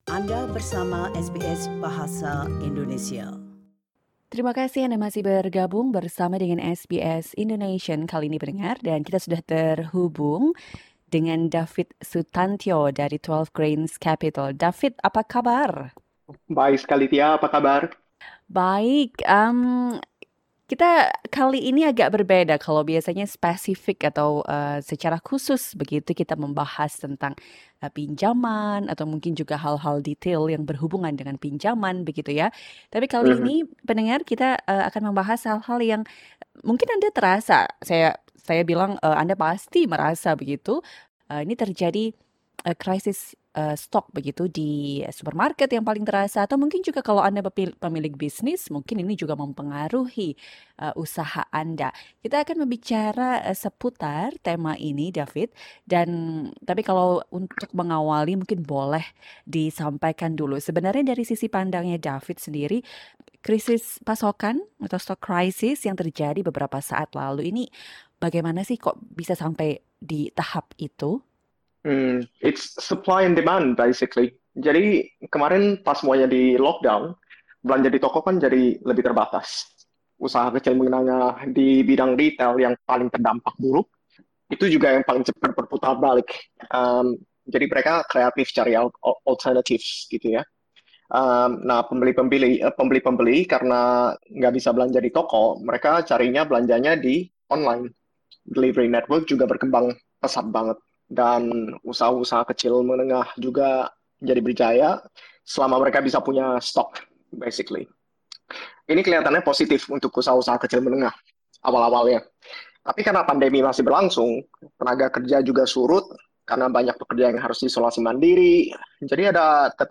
Here's a snippet of the interview.